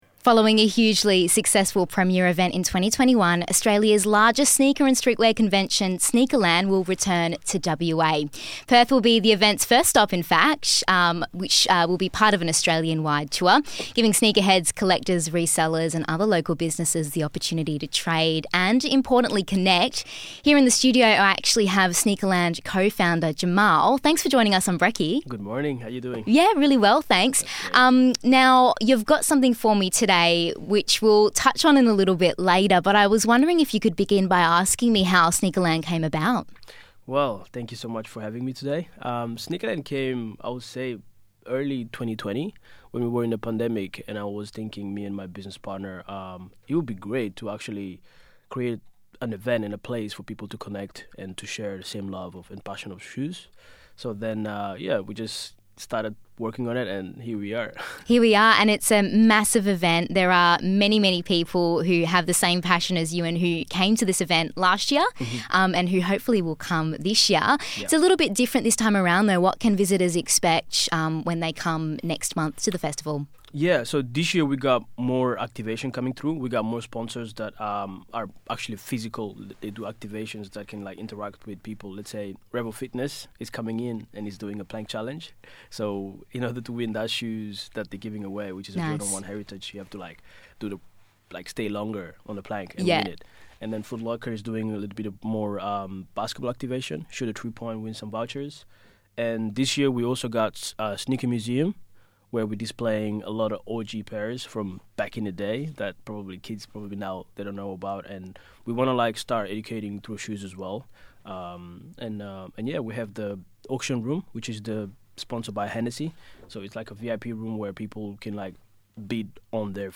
popped by the studio to chat about the festivals new rendition and offer some tips on how to authenticate your kicks.